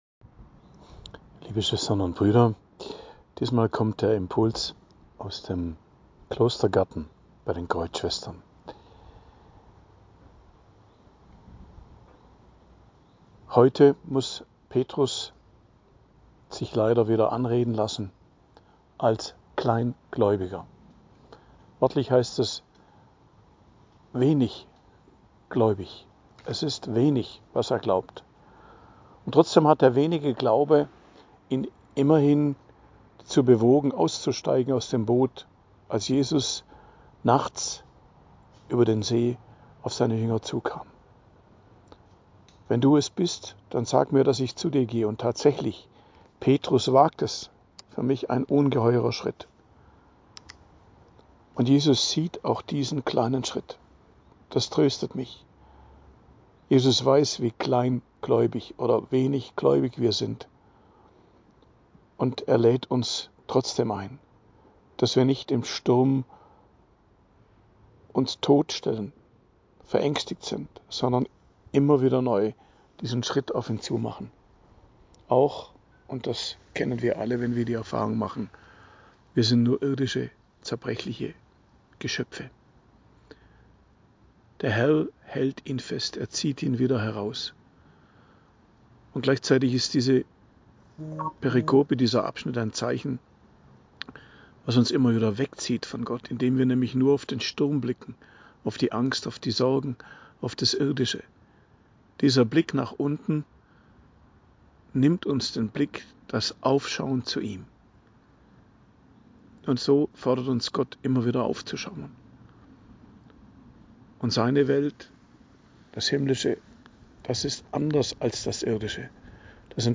Predigt am Dienstag der 18. Woche i.J., 5.08.2025